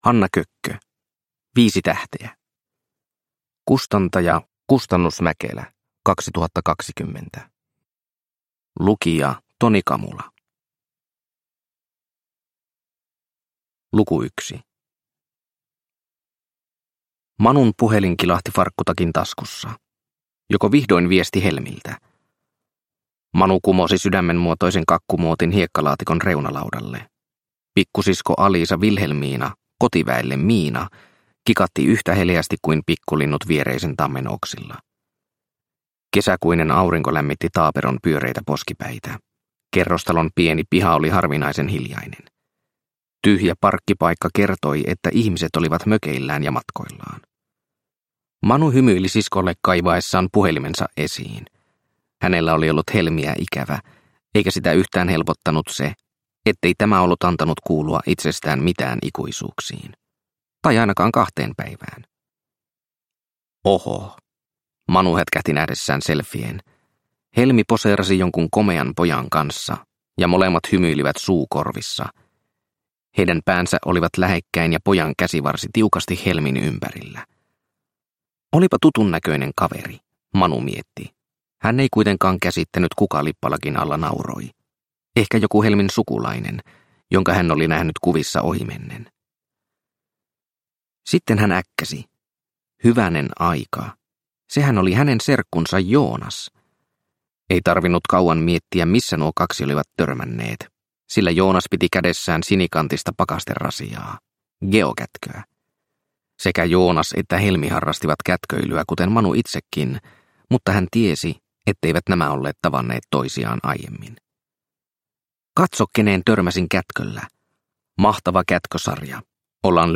Viisi tähteä – Ljudbok – Laddas ner